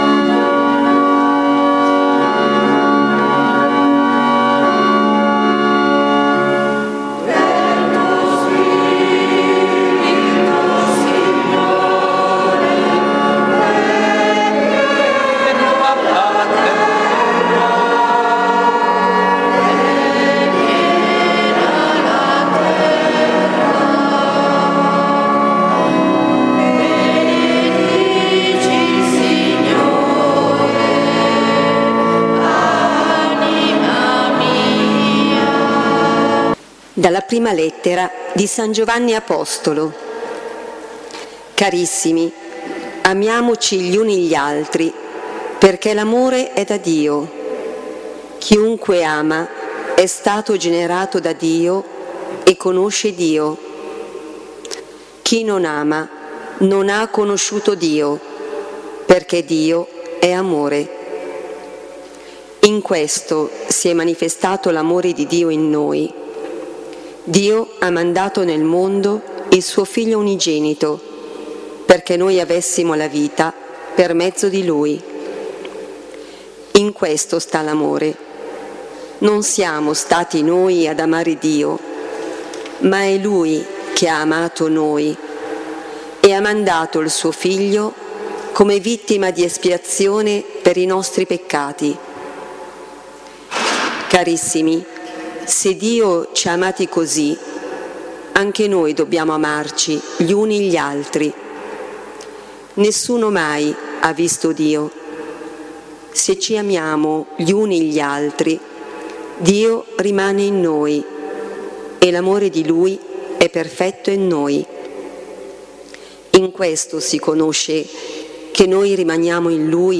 Online l’audio della Lectio / Catechesi del 10 dicembre 23
ore 16.00 | Lectio divina a Giussano